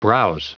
Prononciation du mot browse en anglais (fichier audio)
Prononciation du mot : browse